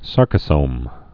(särkə-sōm)